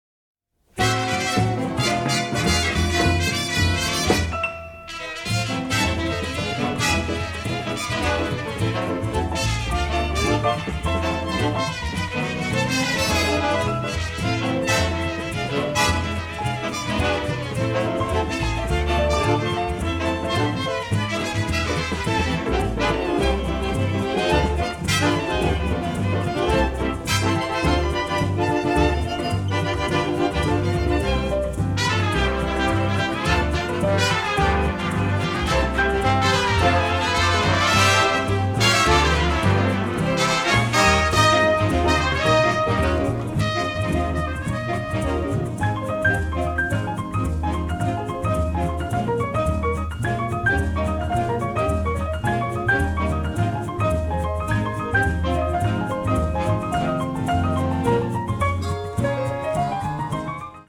synthesizers morphed with traditional orchestral instruments